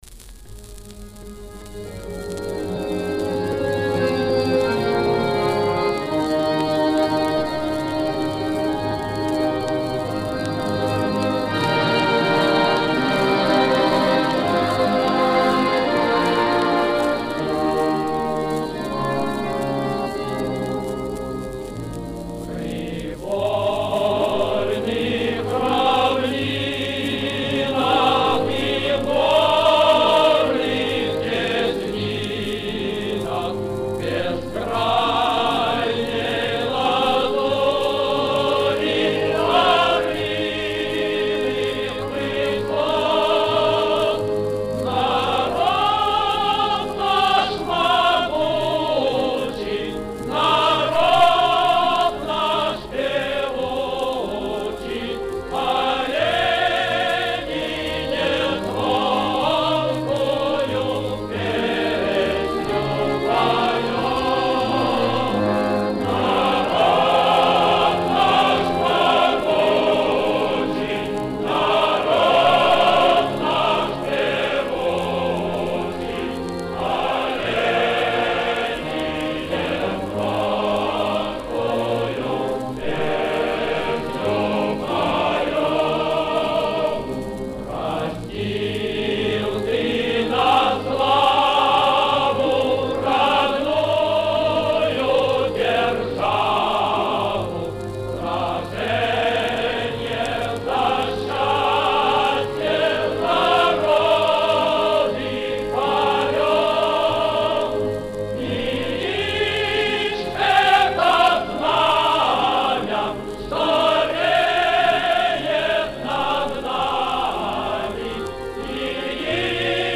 Редкая запись.